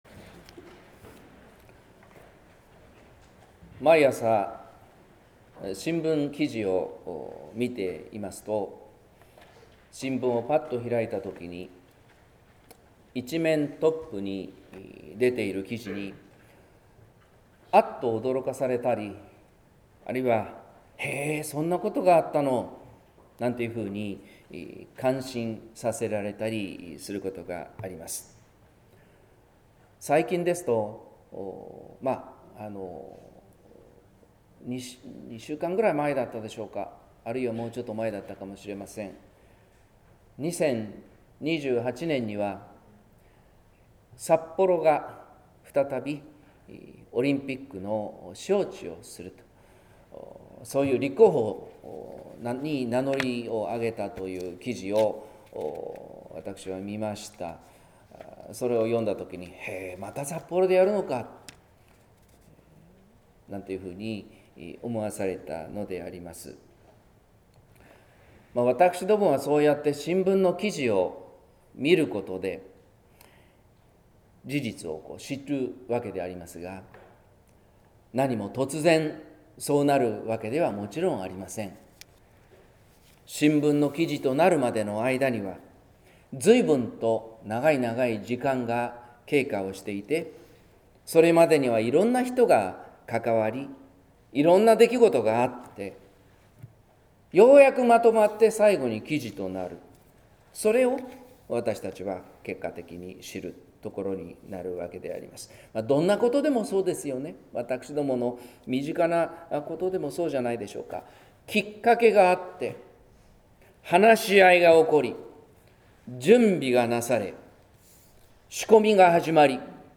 説教「期待と不安」（音声版）